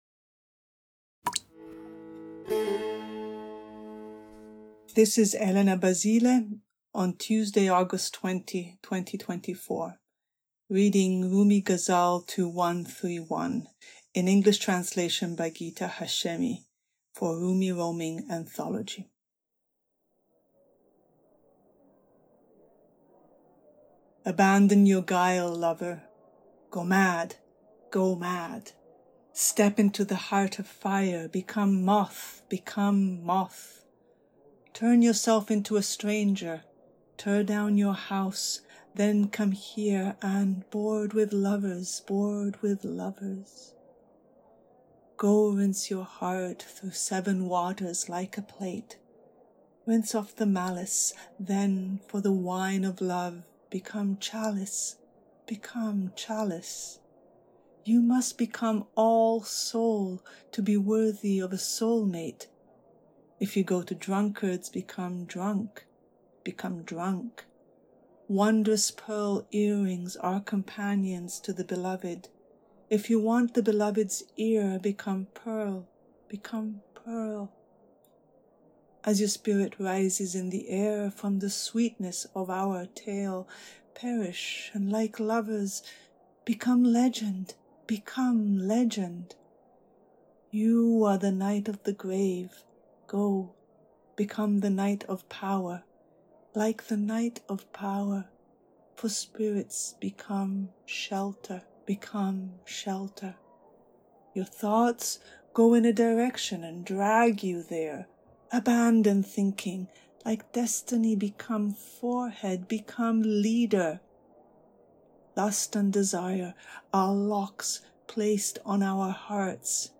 Rumi, Ghazal 2131, Translation, Rumi roaming, Poetry